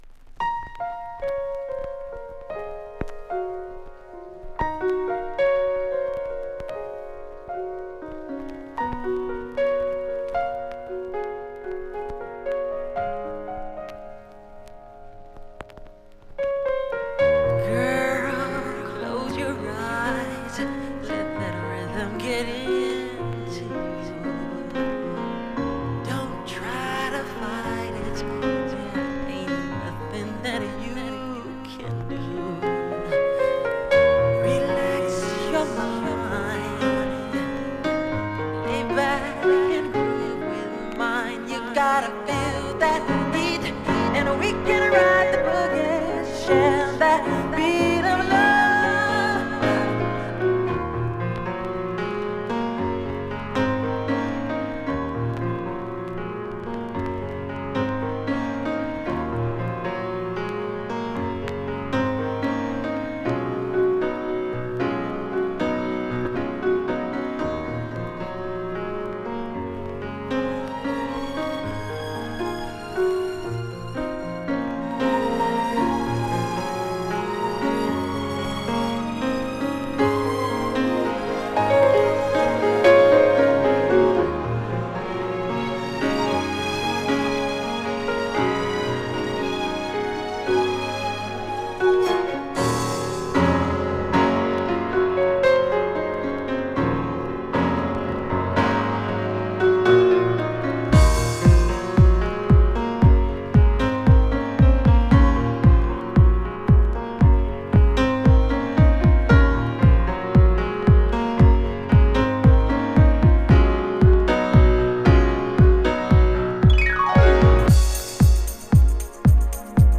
天を仰ぎたくなるようなドラマティックな展開は圧巻!!